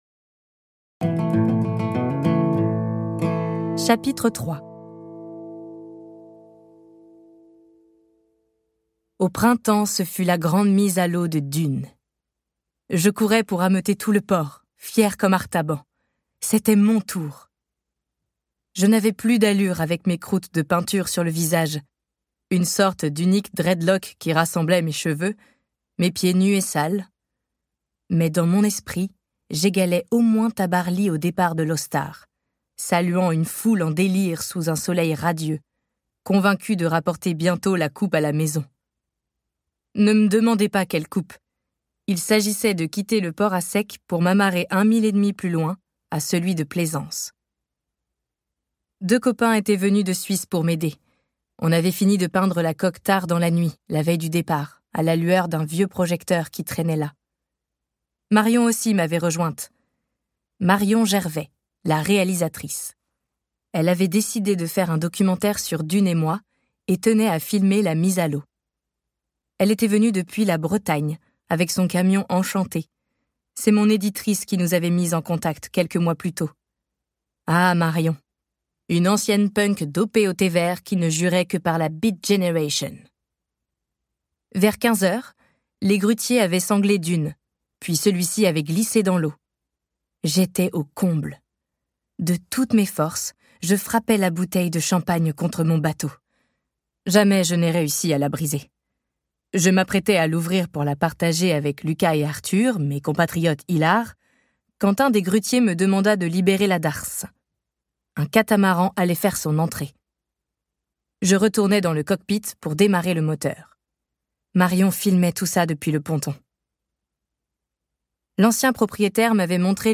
Click for an excerpt - Emmenez-moi de Marion Peronnet, Sarah Gysler